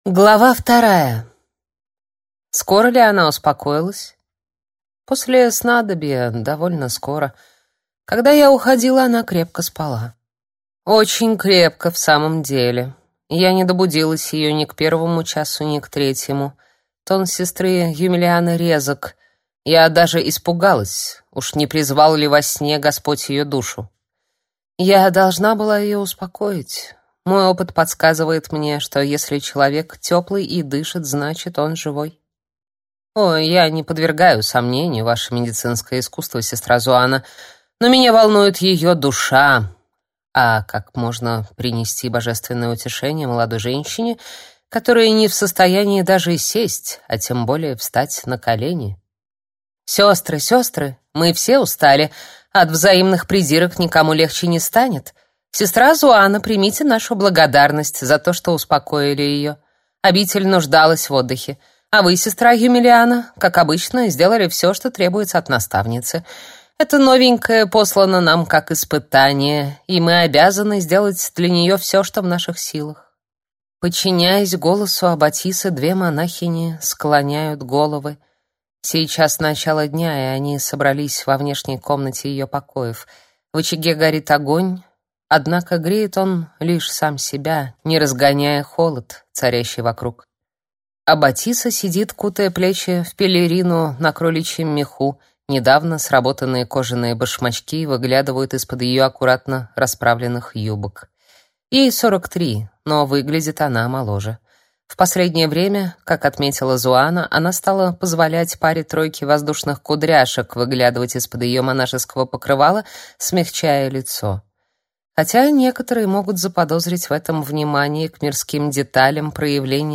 Аудиокнига Святые сердца | Библиотека аудиокниг